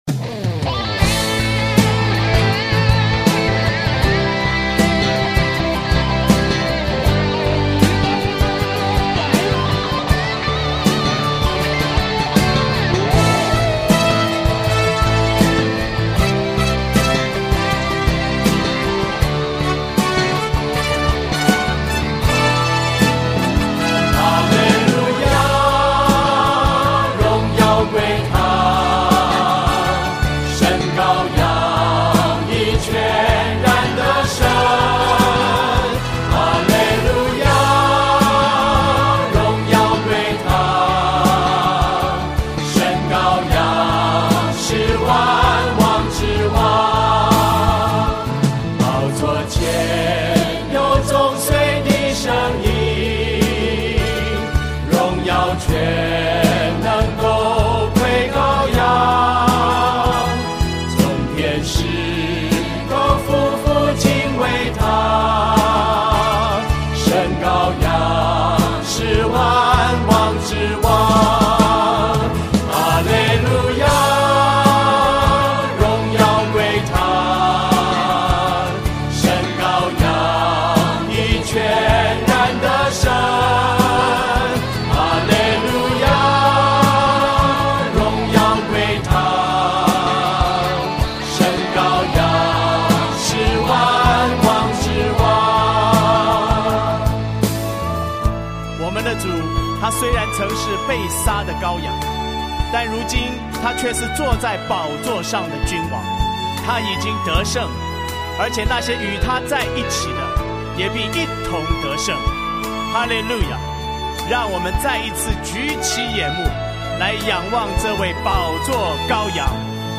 赞美诗 | 神羔羊是万王之王